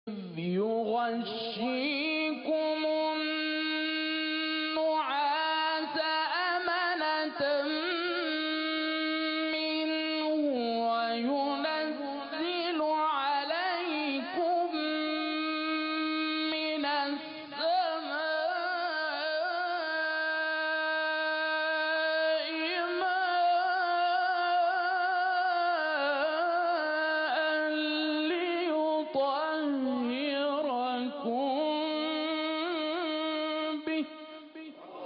شبکه اجتماعی: فرازهای صوتی از تلاوت قاریان ممتاز کشور را می‌شنوید.